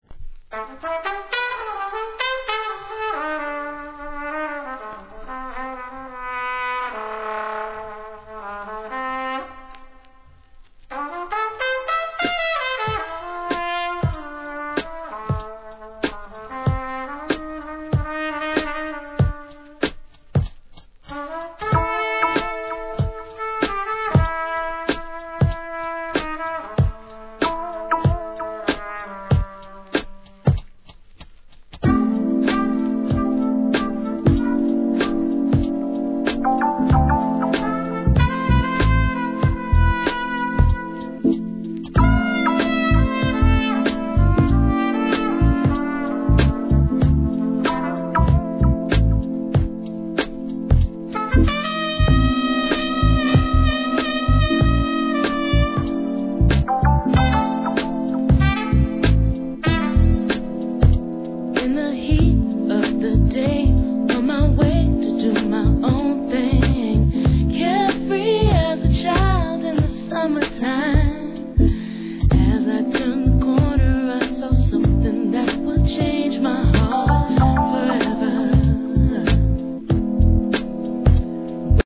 HIP HOP/R&B
女性VO.で怒洒落オツ!